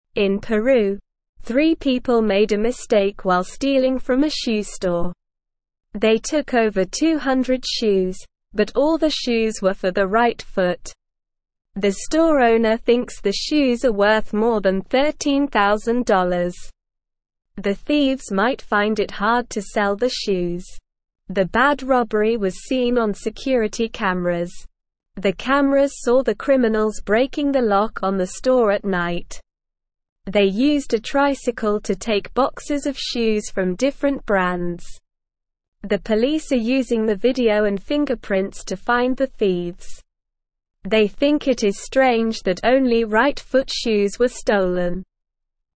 Slow
English-Newsroom-Beginner-SLOW-Reading-Thieves-Steal-200-Right-Foot-Shoes.mp3